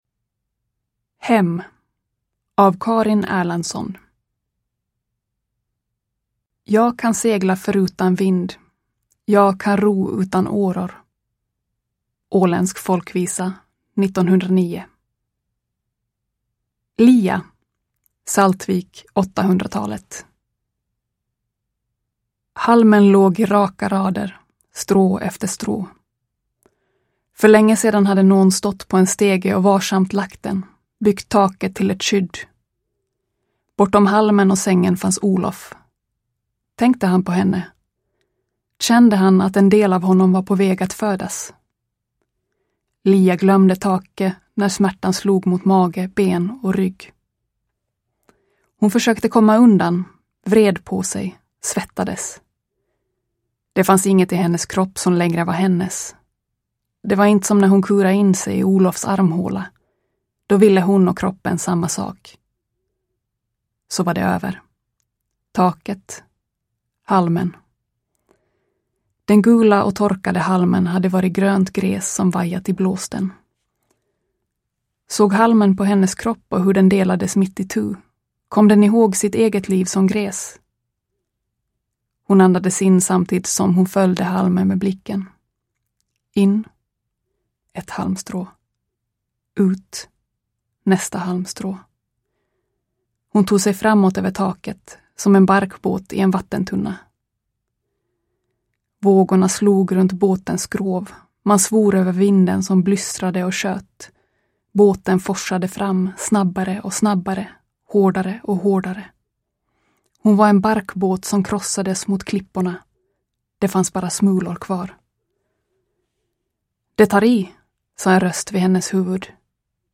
Hem – Ljudbok – Laddas ner